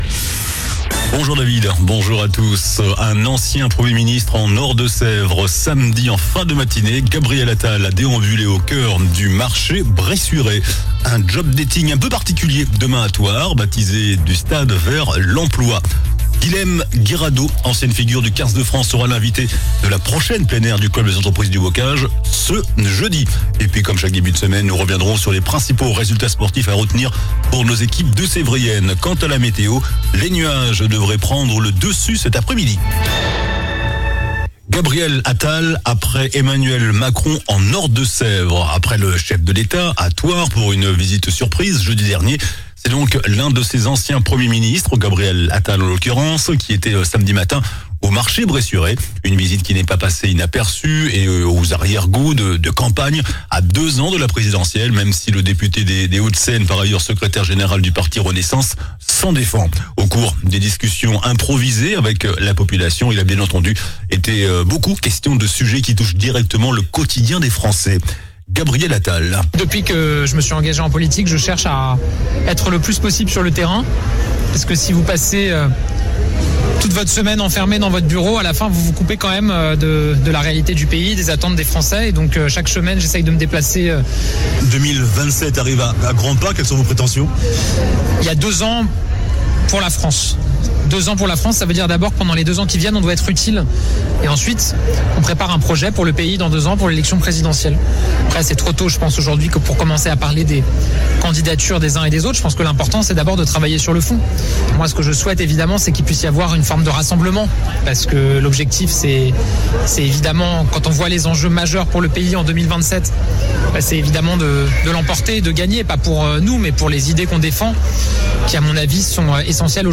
JOURNAL DU LUNDI 14 AVRIL ( MIDI )